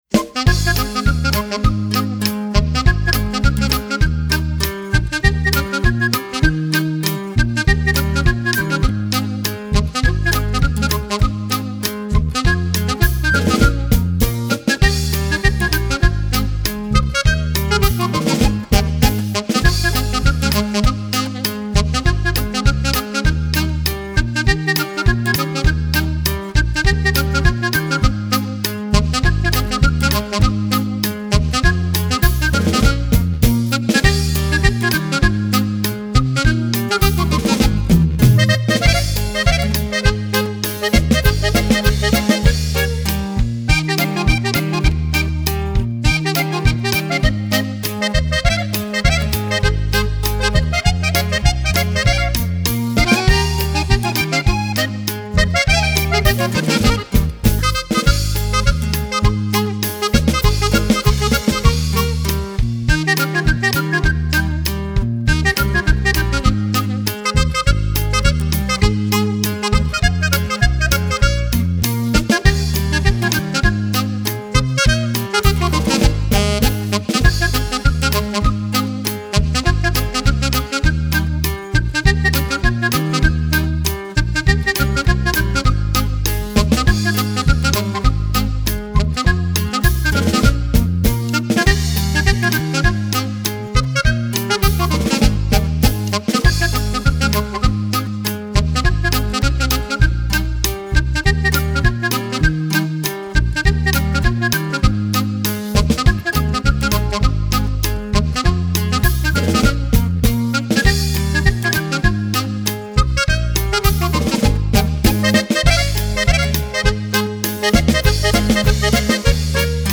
música Regional Mexicana